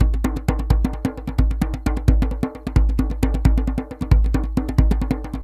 DJEM.GRV02.wav